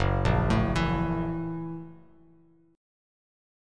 Gb7#9 chord